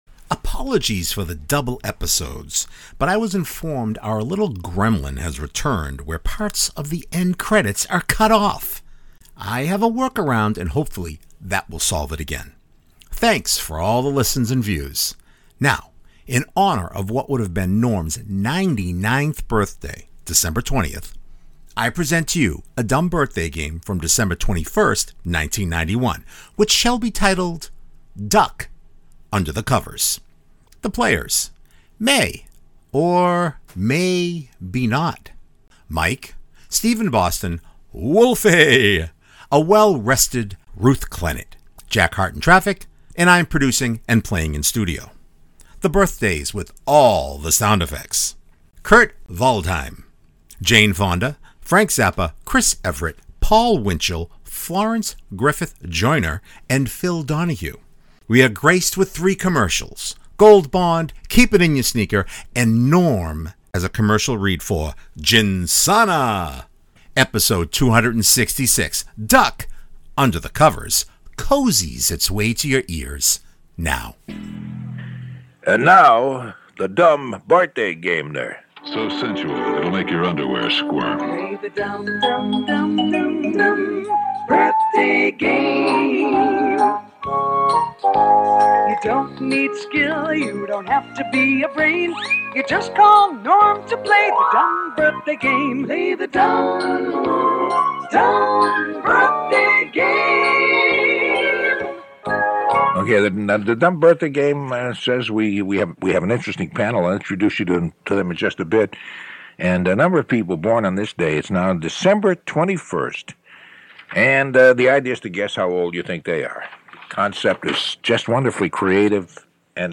Apologies for the double episodes but I was informed our little gremlin has returned where parts of the end credits are cut off.